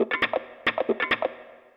K-7 Wah Guitar.wav